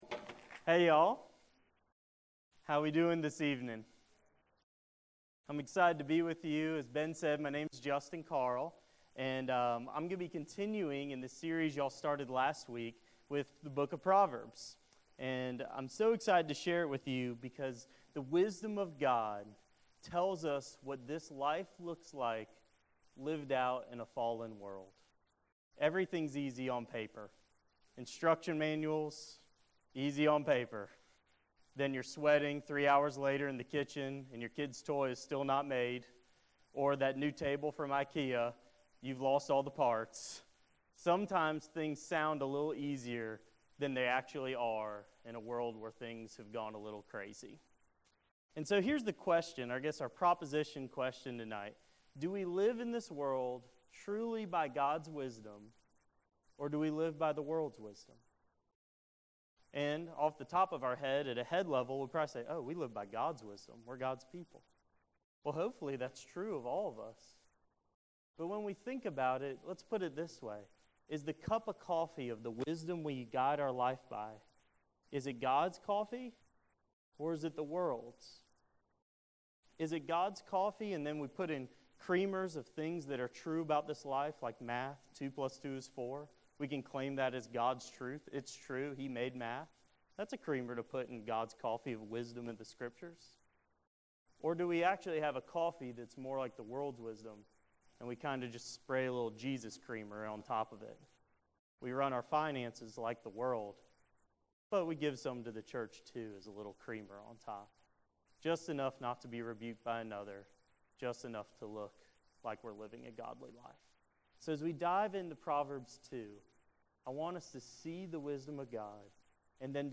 September 29, 2013 PM Bible Study | Vine Street Baptist Church